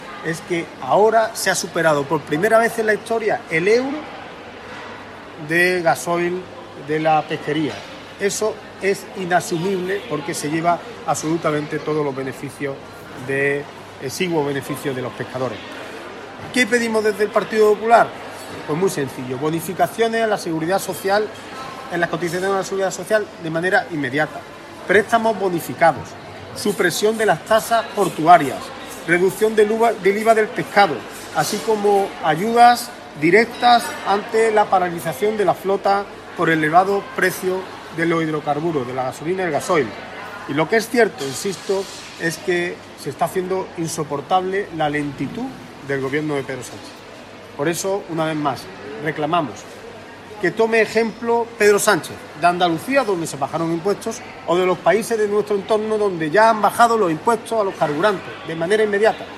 Carmona ha hecho estas declaraciones durante su visita a un mercado de abastos en el municipio malagueño de Antequera, donde ha comprobado las “dificultades” que está ocasionando la situación derivada de la subida de los precios y la consecuente huelga de transportistas.